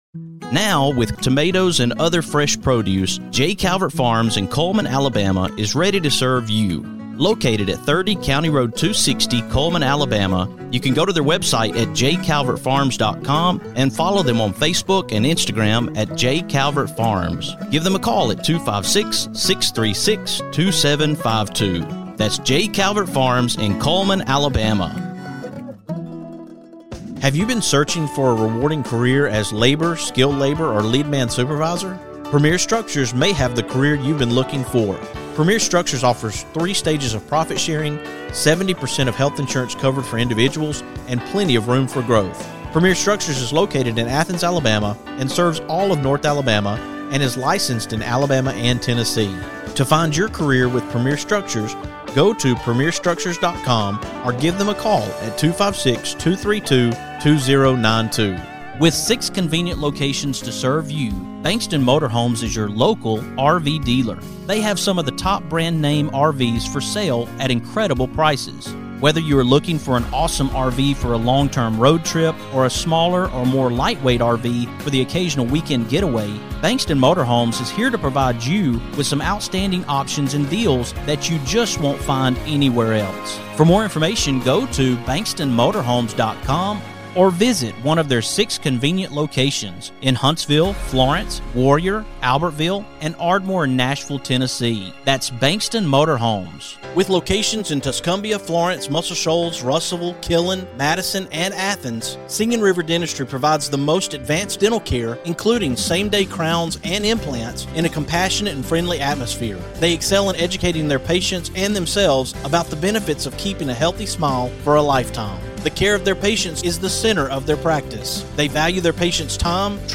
Coaches and players from each team share their passion for the game, the progress they've made through the offseason, and how their communities fuel their drive for success. From small-town pride to powerhouse tradition, these teams are ready to leave it all on the field in 2025.